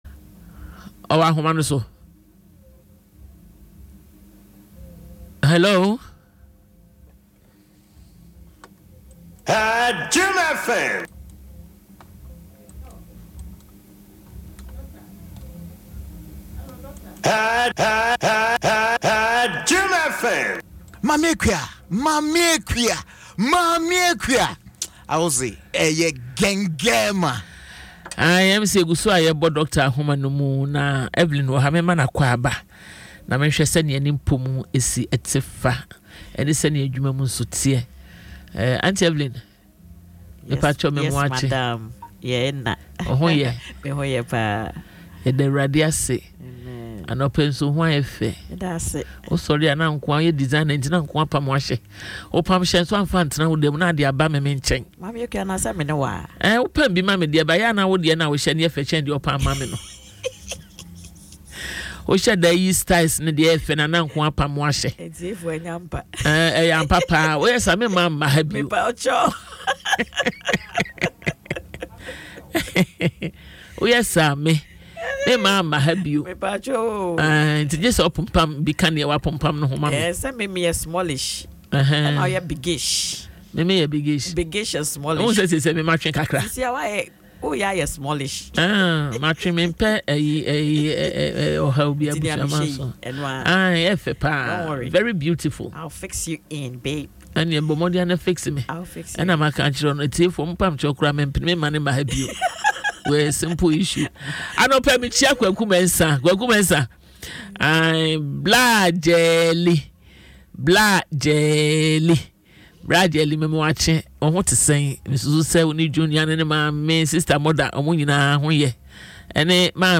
A woman opens up about her emotional journey of struggling with infertility after ten years of marriage, highlighting the disrespect and insensitivity she faces from others who failed to understand her situation